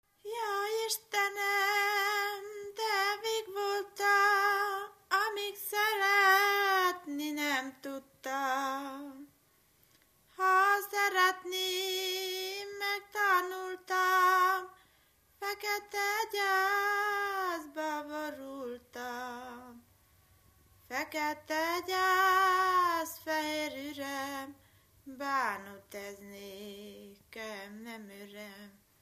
Erdély - Szolnok-Doboka vm. - Feketelak
ének
Stílus: 1.1. Ereszkedő kvintváltó pentaton dallamok
Szótagszám: 8+8.8+8.8.8
Kadencia: 4 (b3) 4 1